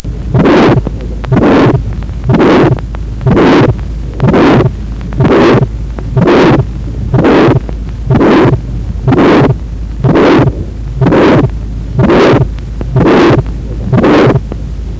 Mid systolic murmur  พบในภาวะที่มีการตีบของหัวใจห้องล่างเช่นที่ infundibular
PAPVR มีลักษณะเป็น cresendo decresendo murmur